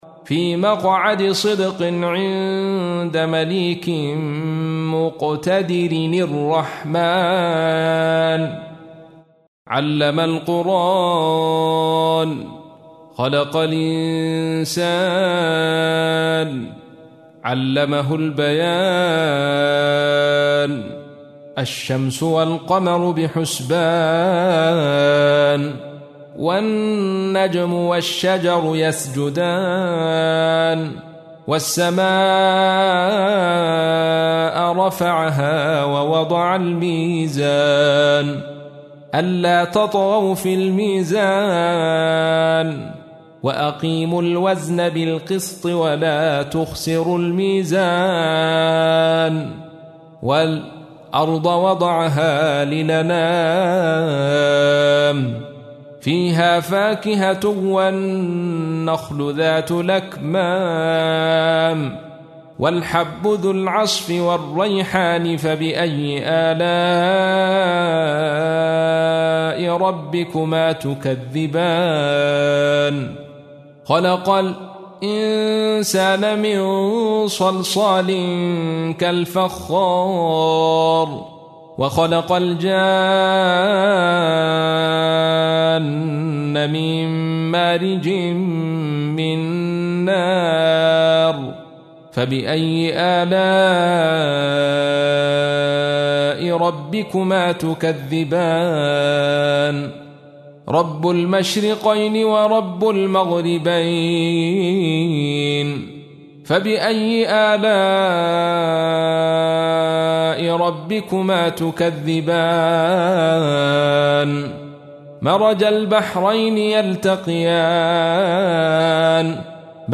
تحميل : 55. سورة الرحمن / القارئ عبد الرشيد صوفي / القرآن الكريم / موقع يا حسين